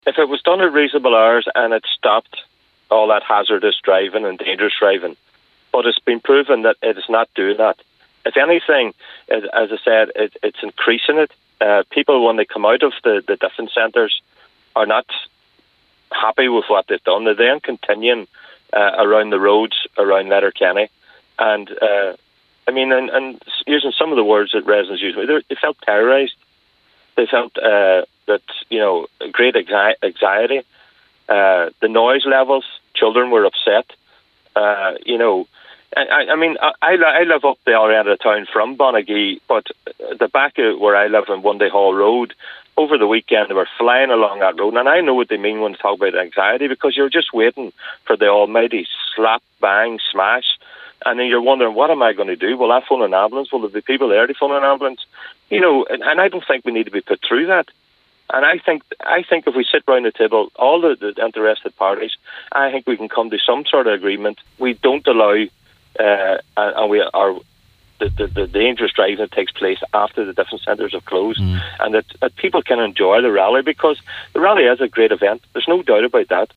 He’s calling for action from all relevant bodies: